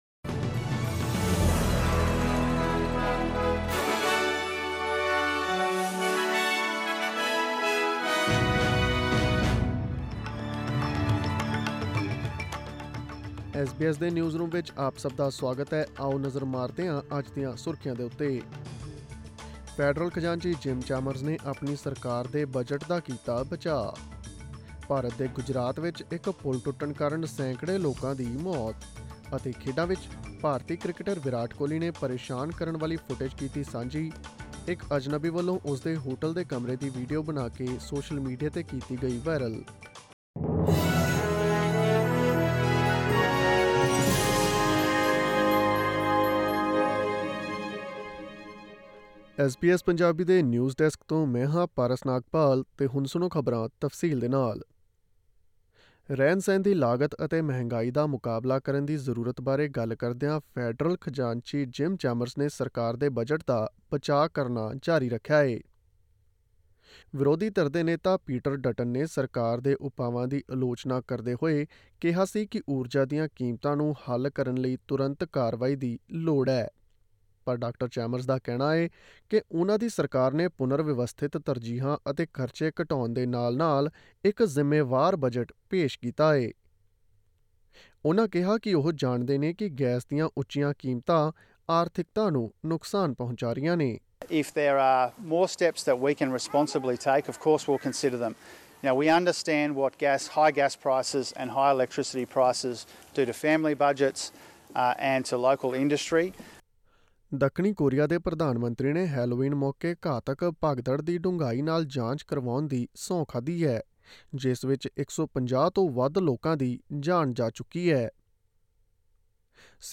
Click on the player at the top of the page to listen to this news bulletin in Punjabi.